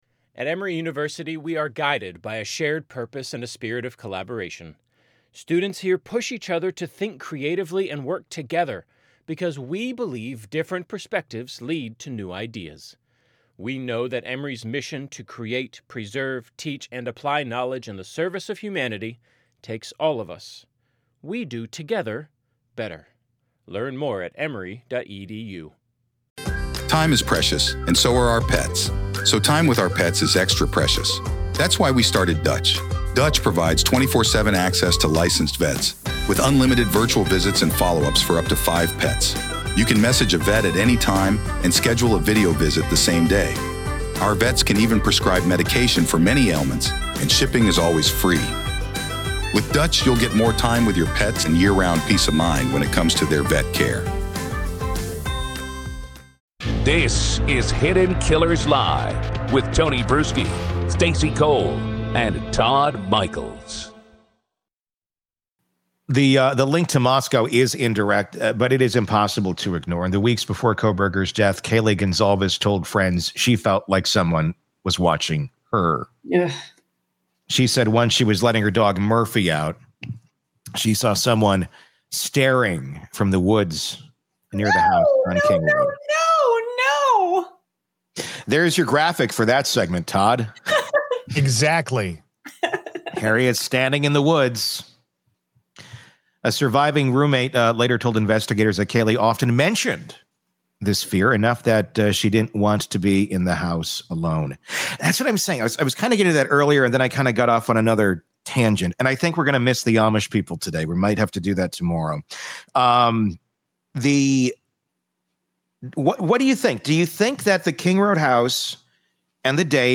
The result is a gripping combination of live banter and expert analysis, tying together narcissism, numerology, forensic details, and FBI insight into what might still be hidden out there.